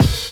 0202 DR.LOOP.wav